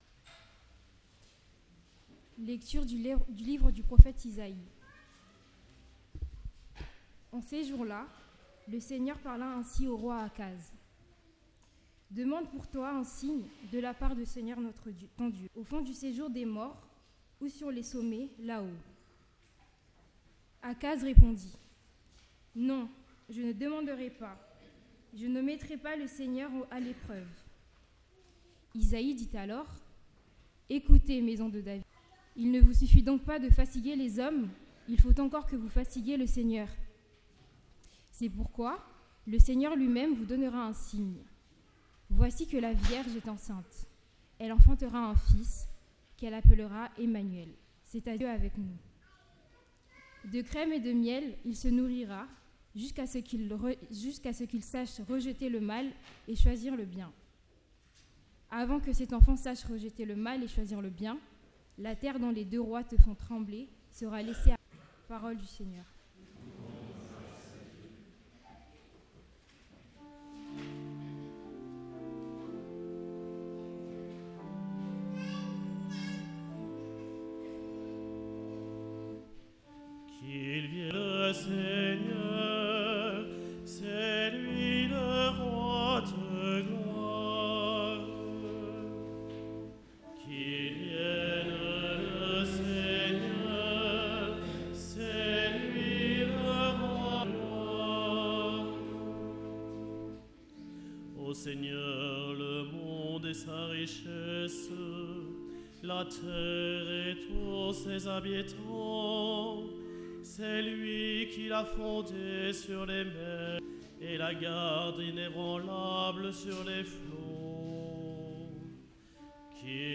Parole de Dieu & Sermon du Dimanche 18 Décembre 2022